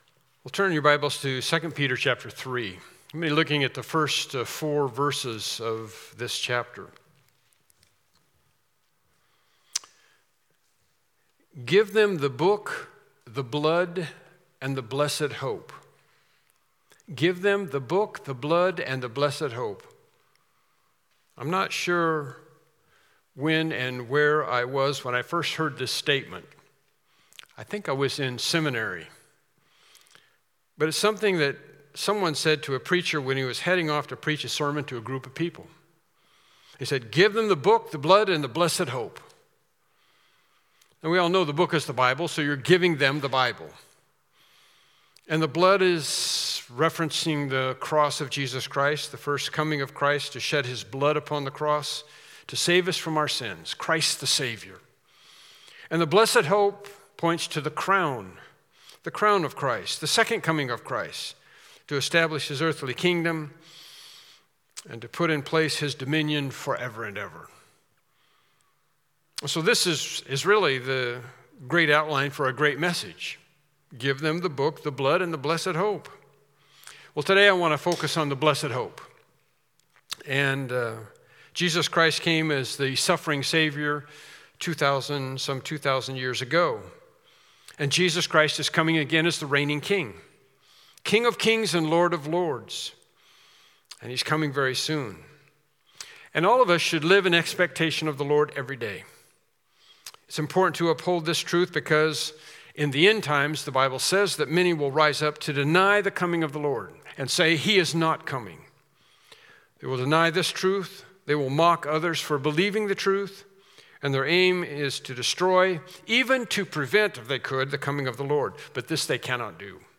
2 Peter 3:1-4 Service Type: Morning Worship Service « Lesson 6